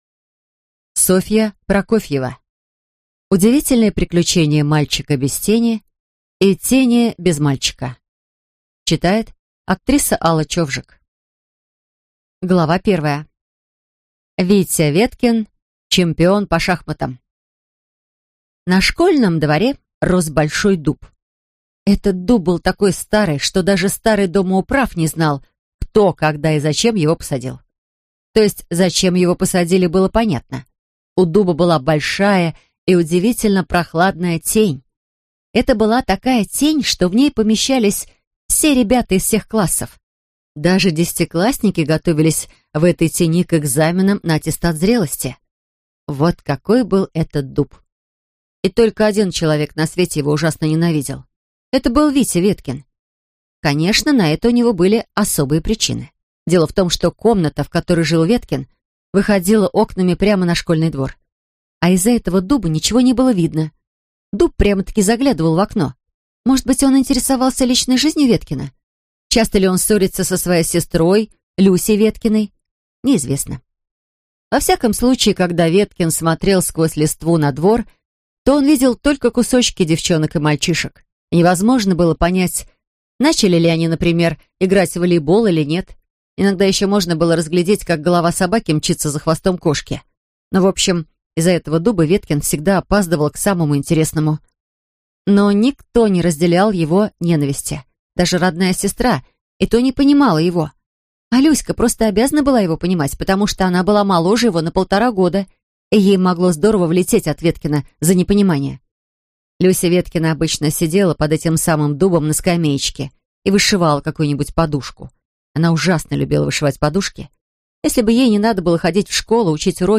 Аудиокнига Удивительные приключения мальчика без тени и тени без мальчика | Библиотека аудиокниг